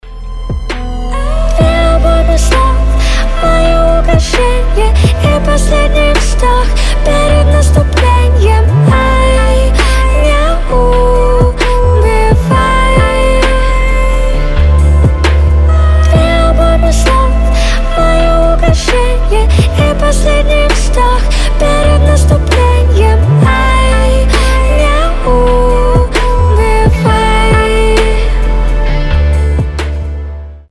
• Качество: 320, Stereo
Хип-хоп
женский рэп
красивый голос